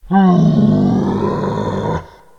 moan-1.ogg